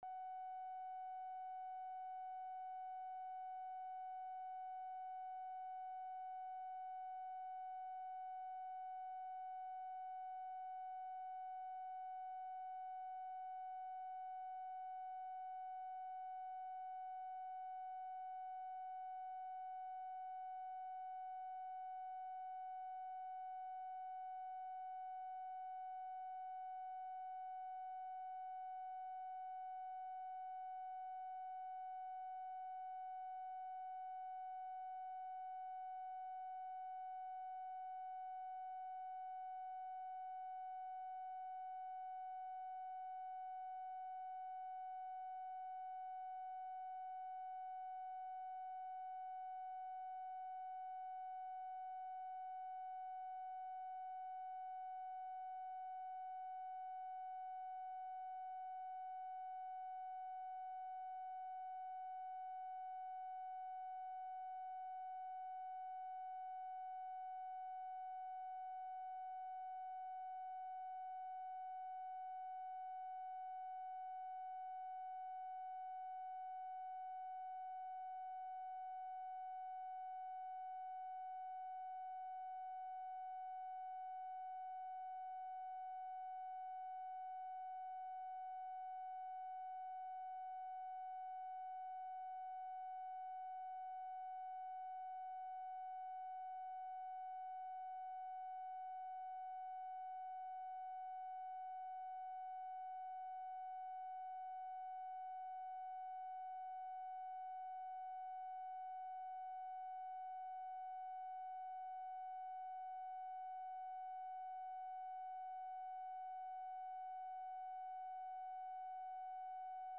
Location of Speaker 1: LBJ Ranch, near Stonewall, Texas Subject: LBJ personal; Texas politics
POOR SOUND QUALITY
Specific Item Type: Telephone conversation
Format: Dictation belt